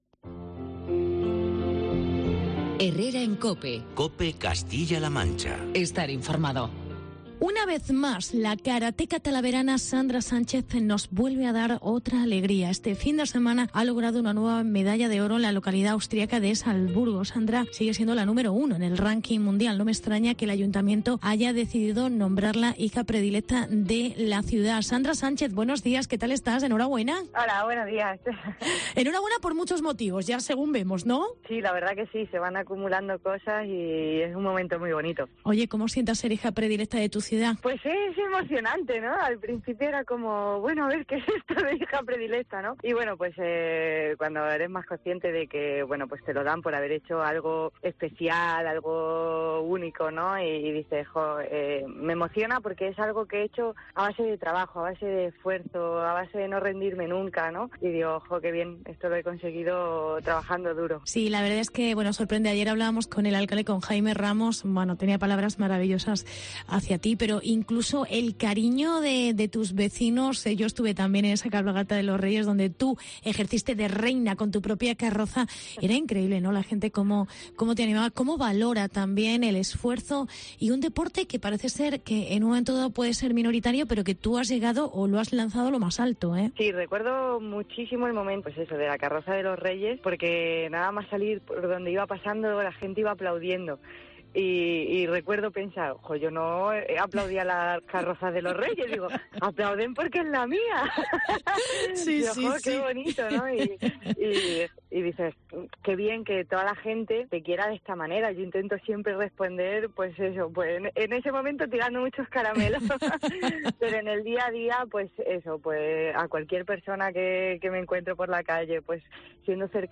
La karateca Nº 1 del Mundo nombrada "Hija Predilecta" de Talavera. Entrevistamos a la talaverana